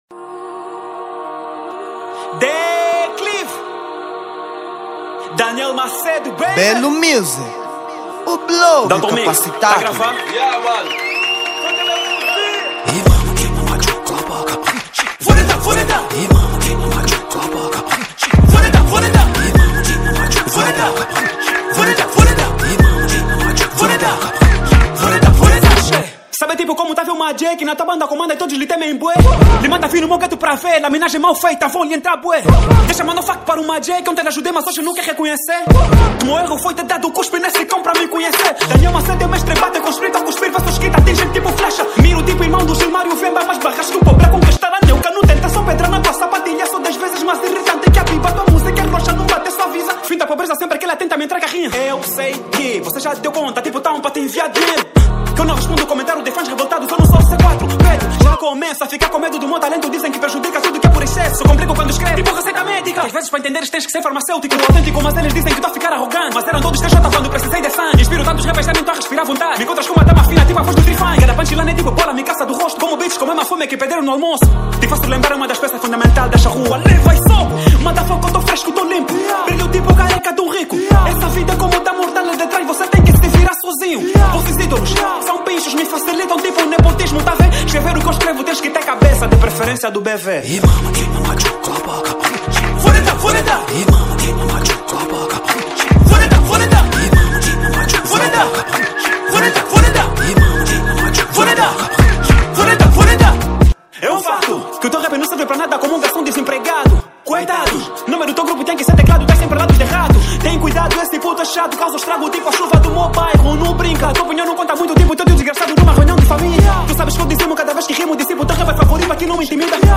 Género: Rap